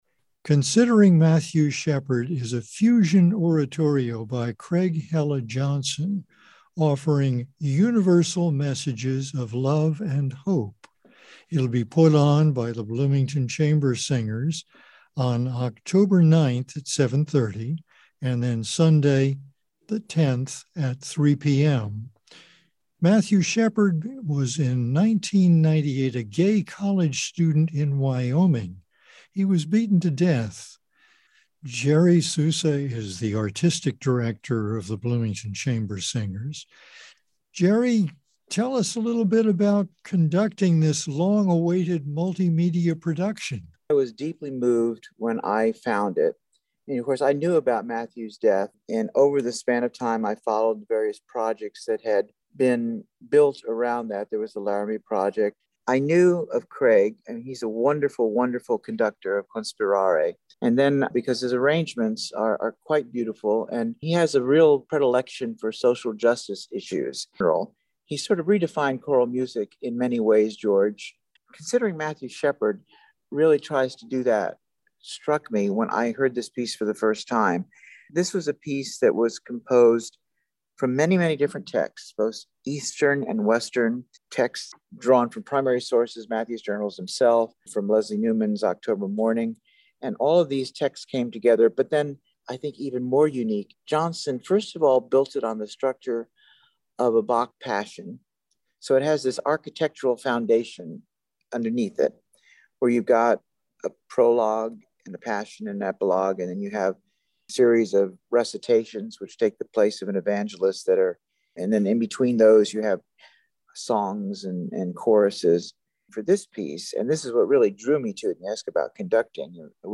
considering mathew shephard interview 3 .mp3